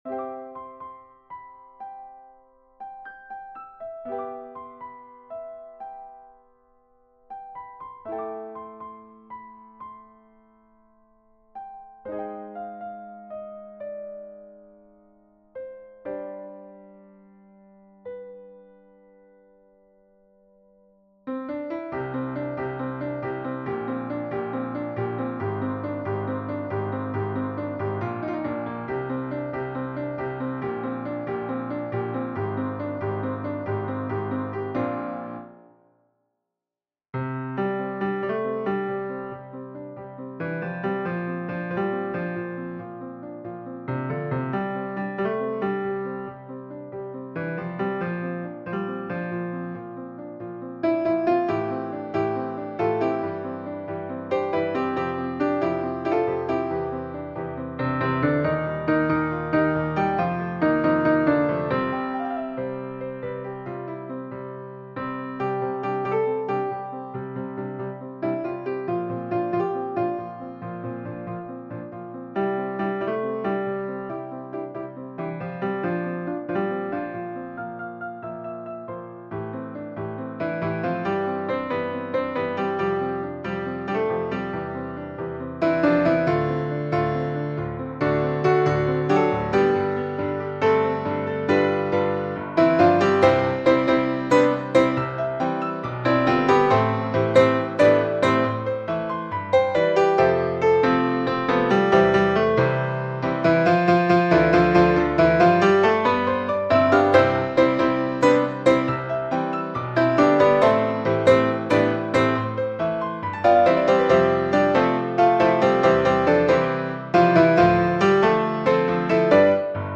SATB mixed choir and piano
世俗音樂